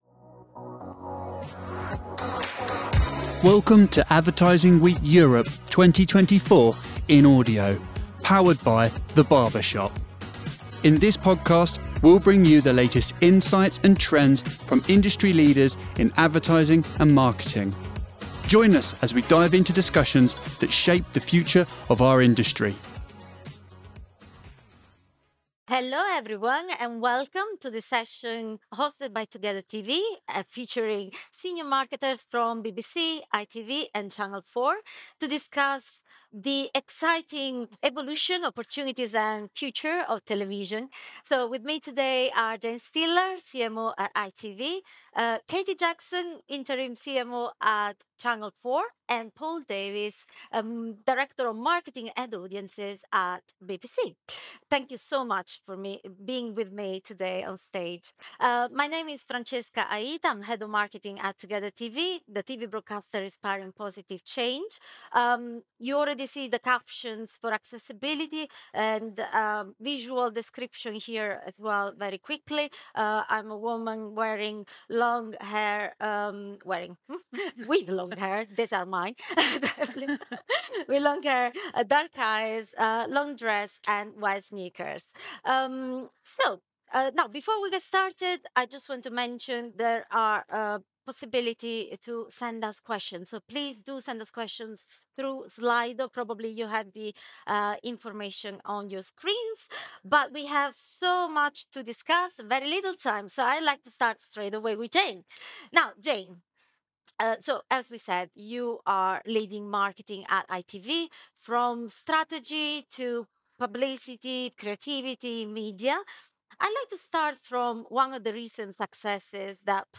Learn how British TV is adapting to the rise of streaming platforms, the impact of digital transformation, and what it means for content creation and audience engagement. This session offers a deep dive into the future of British television, providing valuable insights for media professionals and viewers alike.